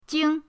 jing5.mp3